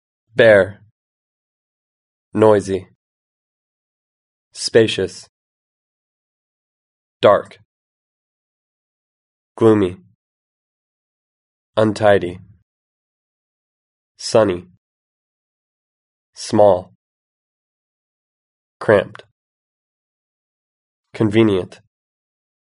Listen to how these words are pronounced.